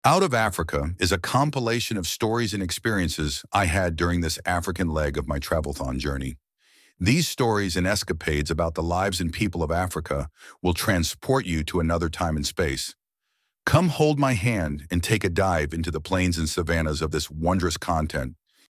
PLAY VOICE SAMPLE